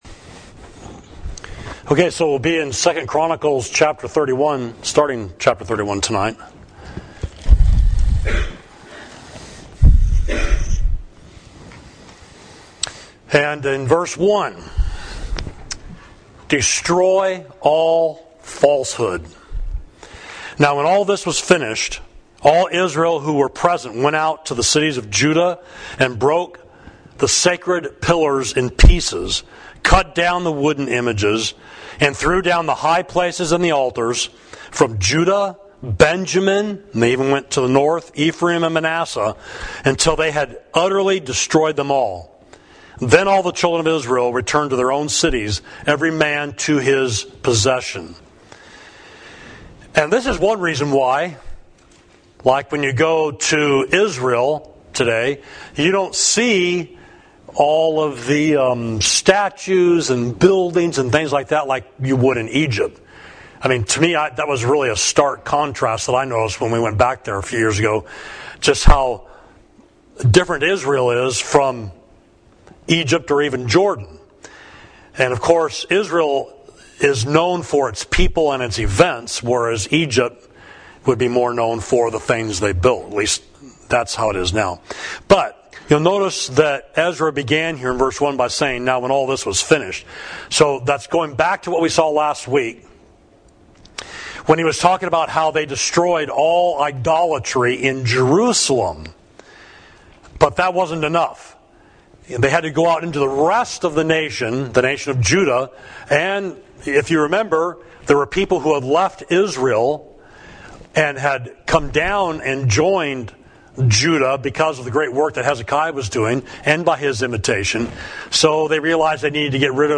Sermon: There Are More with Us than with Them